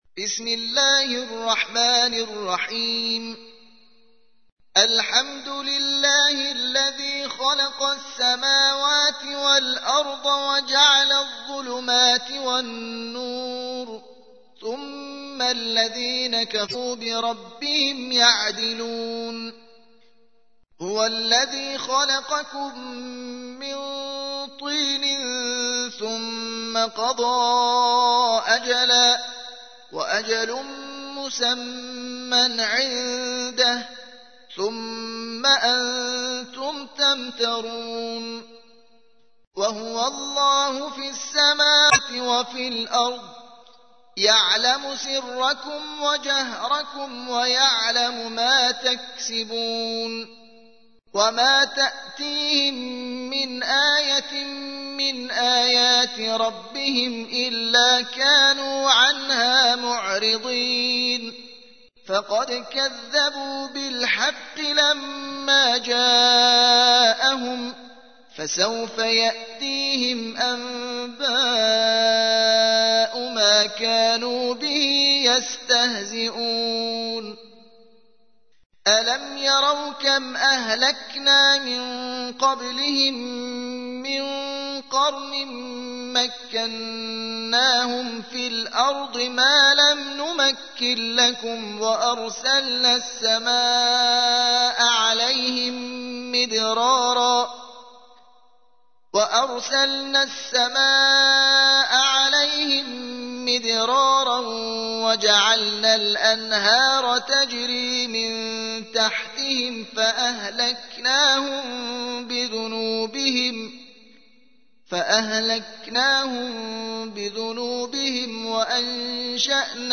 6. سورة الأنعام / القارئ